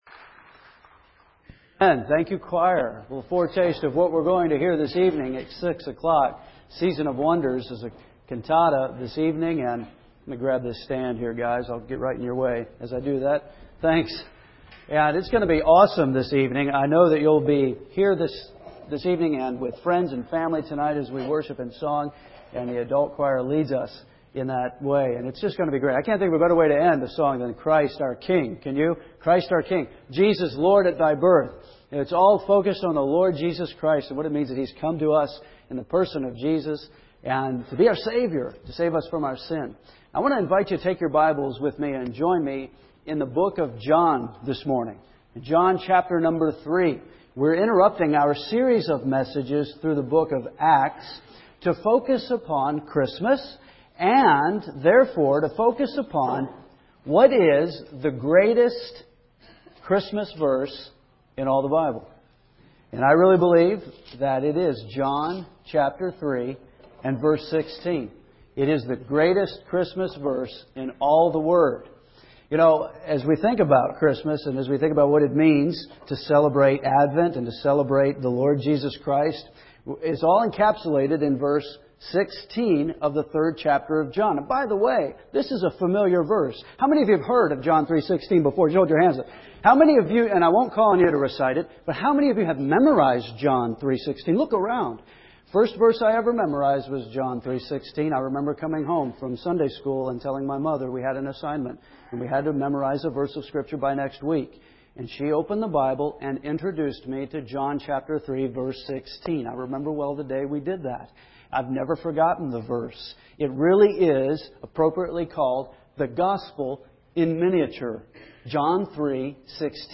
A Christmas Message